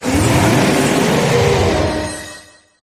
eternatus_ambient.ogg